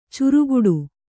♪ curuguḍu